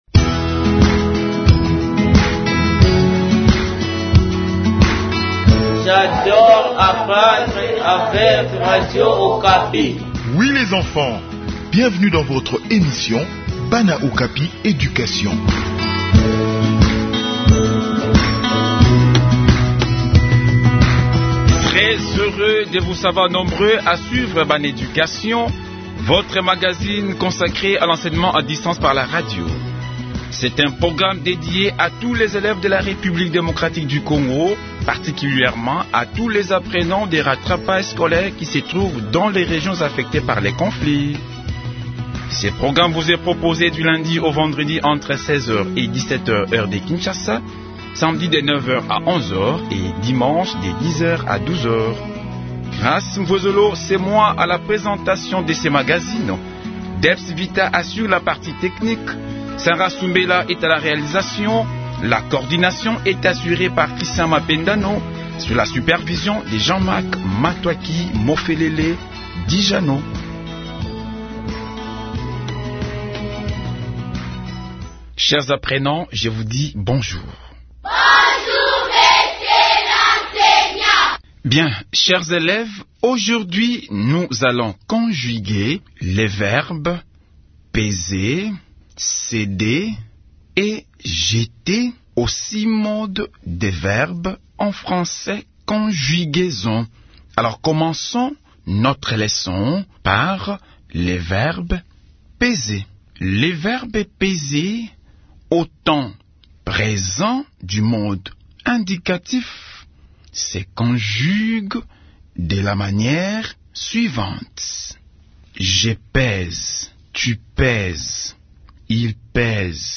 Enseignement à distance : leçon de français sur la conjugaison des verbes peser, jeter et céder